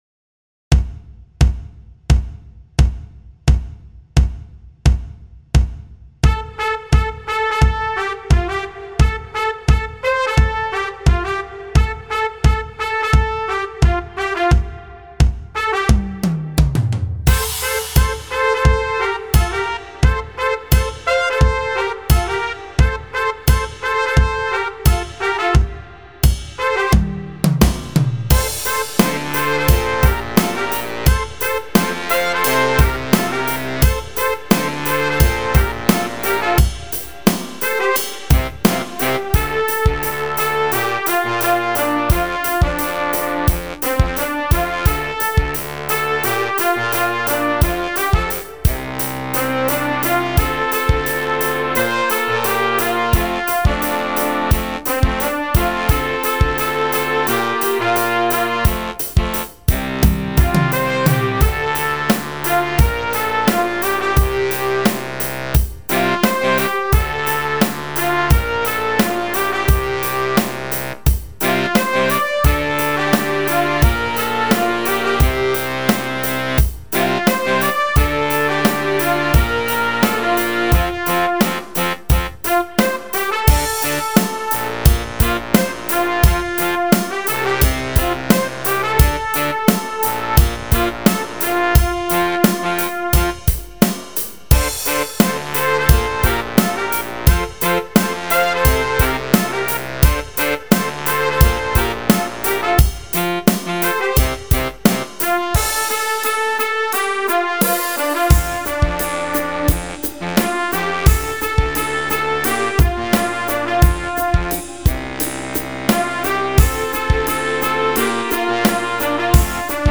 Bläserarrangement lang